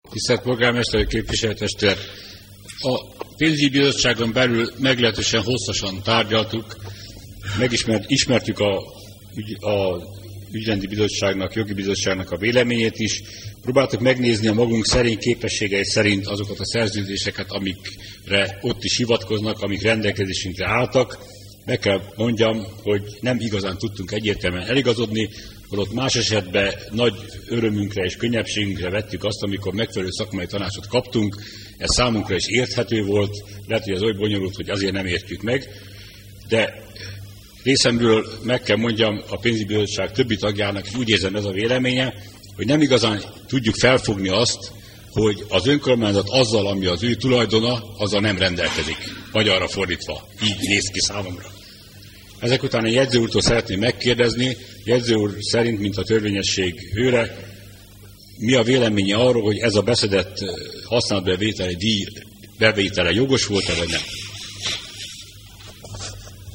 A Képviselő-testület hangos jegyzőkönyveinek archívuma a 2000. januári megjelenésekor szintén nóvumnak számított, nem csak a SzentesInfo szerveren, de országosan is.
A testületi ülések jegyzőkönyvei - hanggal és videókkal